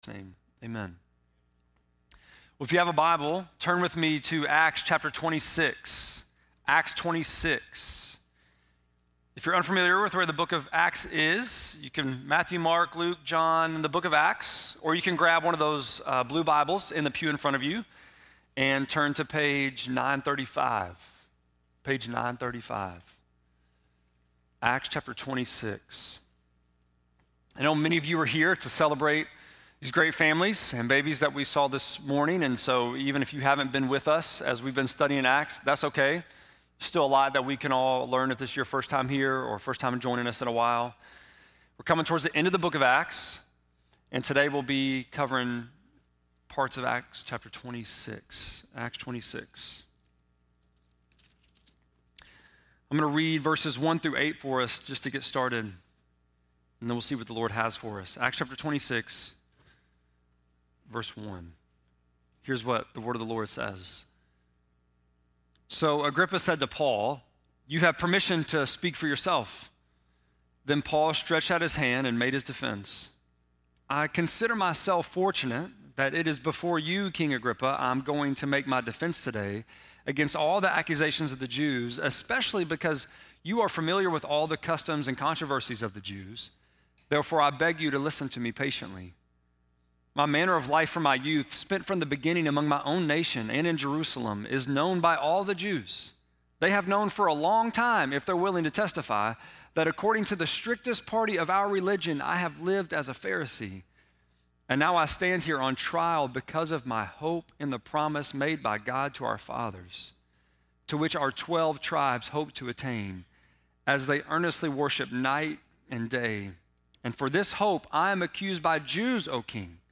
11.17-sermon.mp3